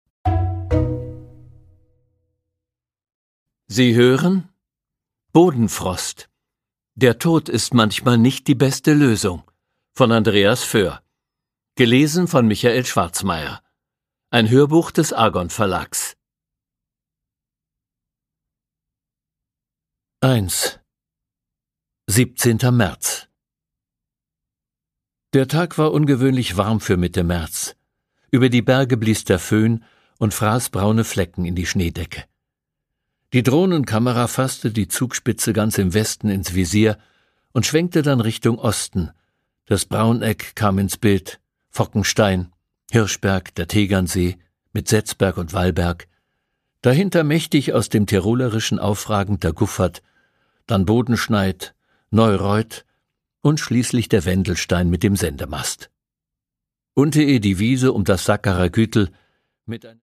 Produkttyp: Hörbuch-Download
auf unnachahmliche Weise bajuwarisch.